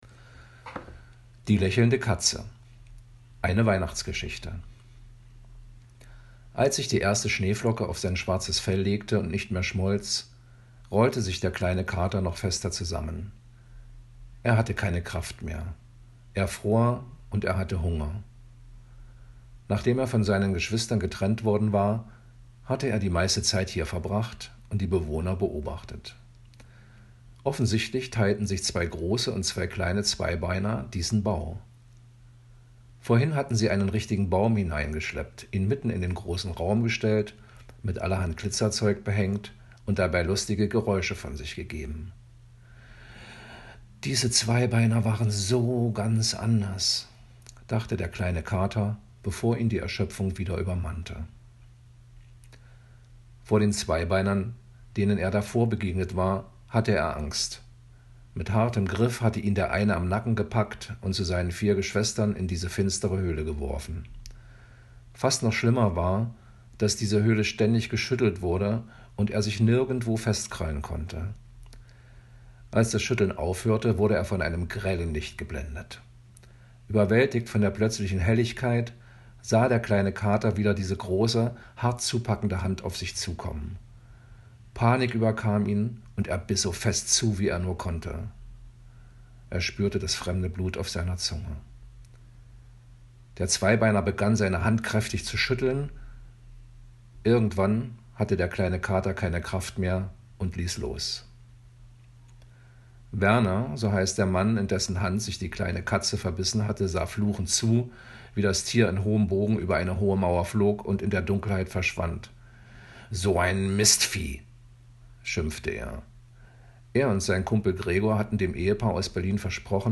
Die Geschichte zum Hören